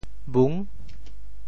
闻（聞） 部首拼音 部首 门 总笔划 9 部外笔划 6 普通话 wén 潮州发音 潮州 bhung6 文 潮阳 bhung6 澄海 bhung6 揭阳 bhung6 饶平 bhung6 汕头 bhung6 中文解释 闻 <动> (形声。